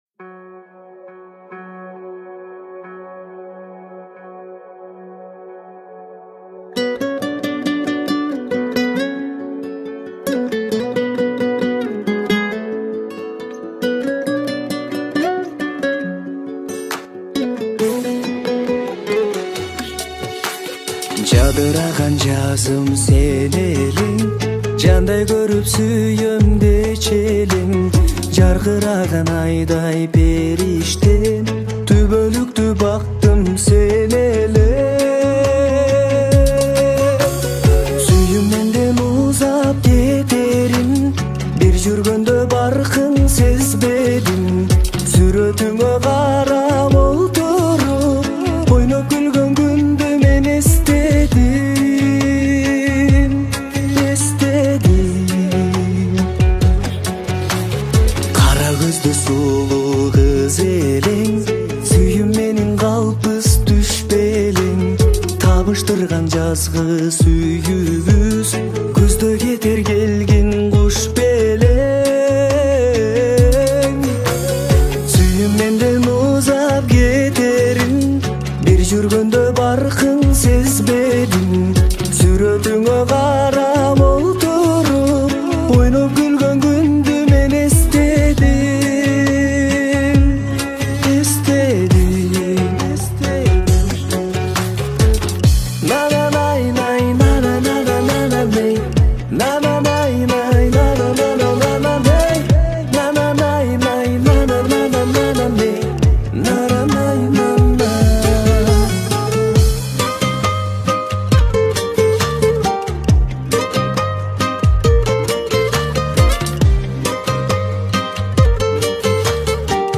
Трек размещён в разделе Русские песни / Киргизская музыка.